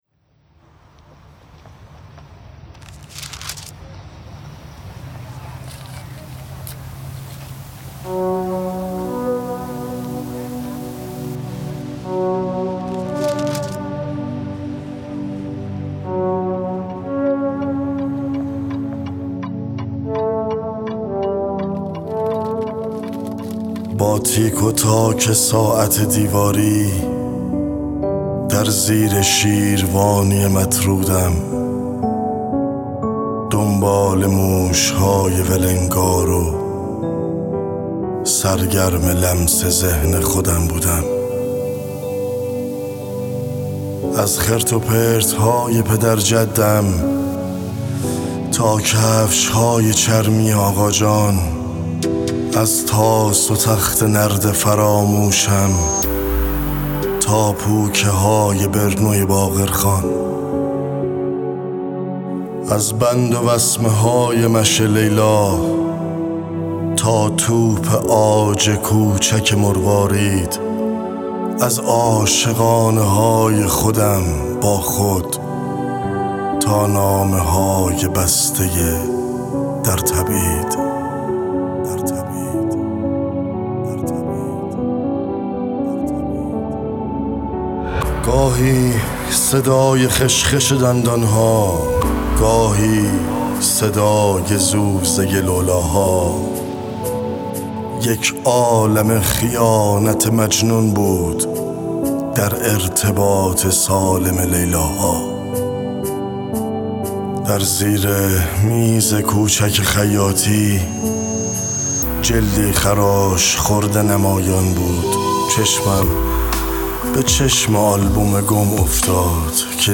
دانلود دکلمه آلبوم با صدای علیرضا آذر